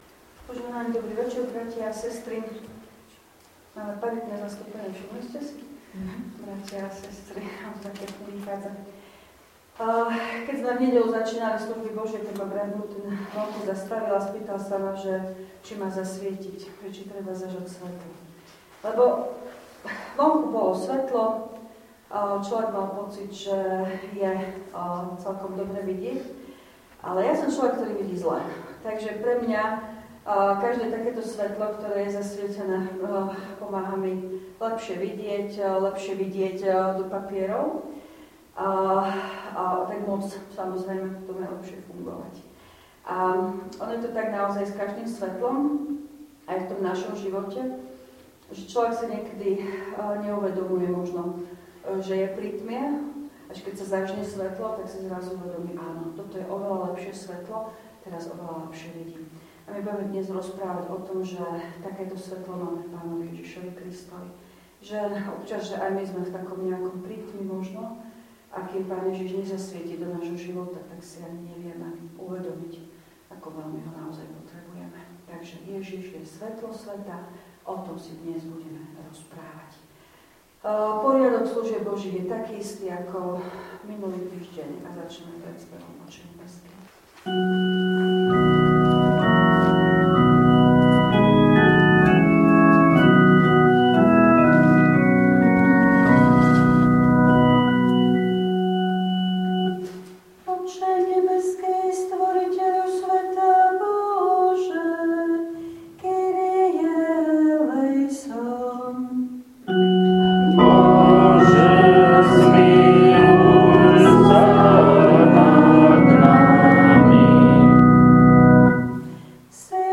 V nasledovnom článku si môžete vypočuť zvukový záznam z večerných pôstnych služieb Božích_26_3_2025.